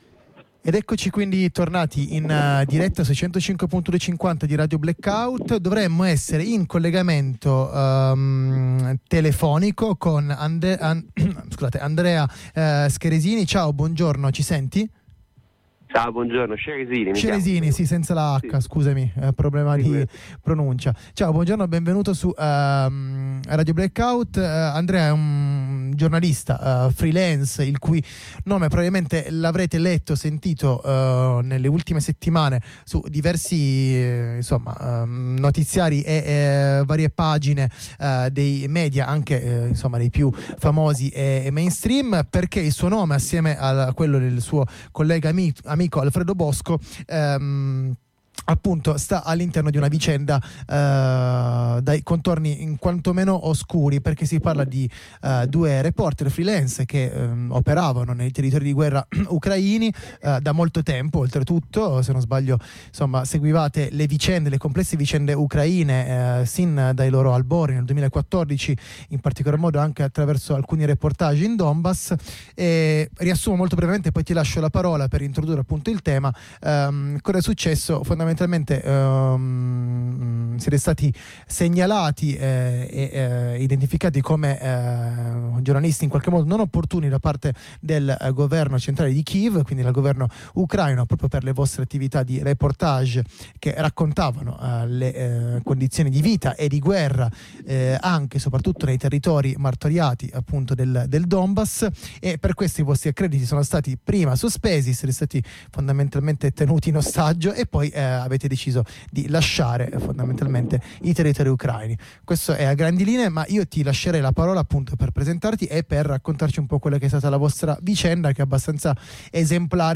freelance intervistato ai nostri microfoni